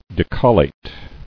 [de·col·late]